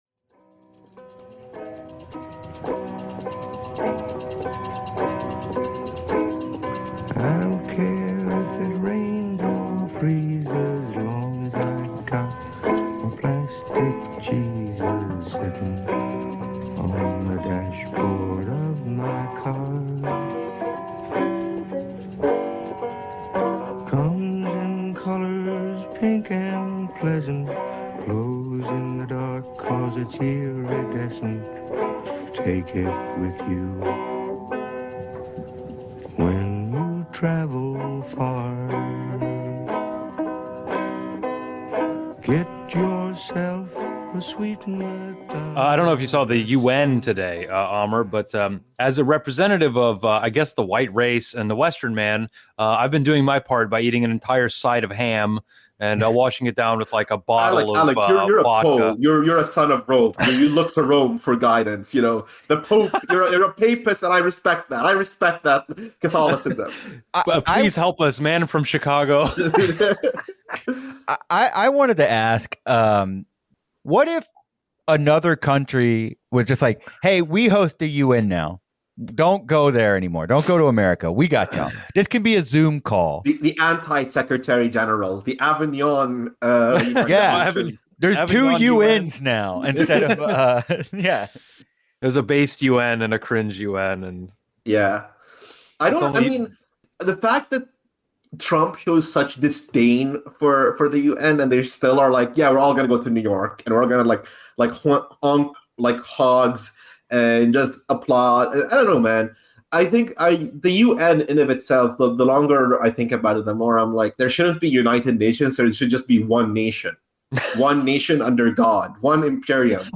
Two lifelong friends and propaganda lovers from the Gulf Coast look at and discuss media portrayals of politics from ongoing news media narratives to film and television.